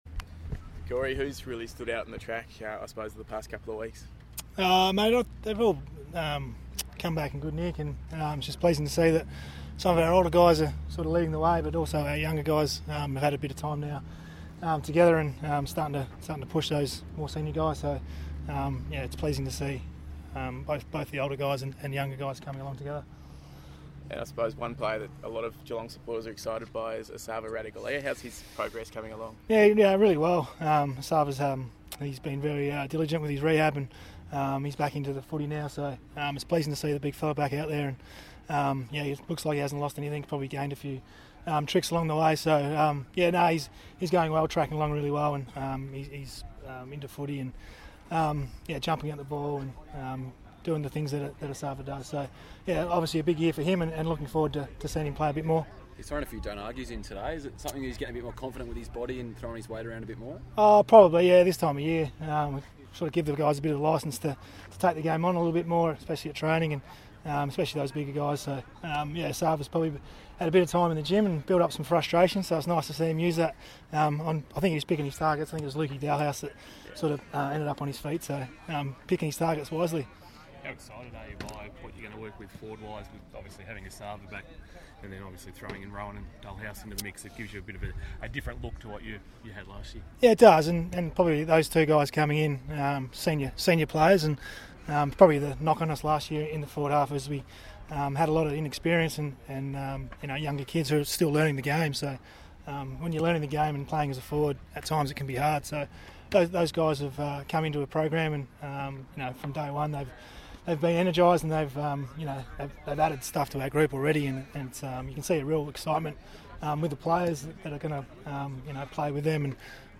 Geelong assistant coach Corey Enright fronted the media on Wednesday.